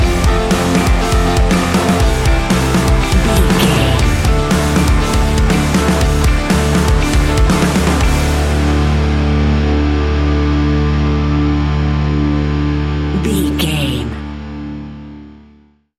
Ionian/Major
D♭
hard rock
heavy metal
instrumentals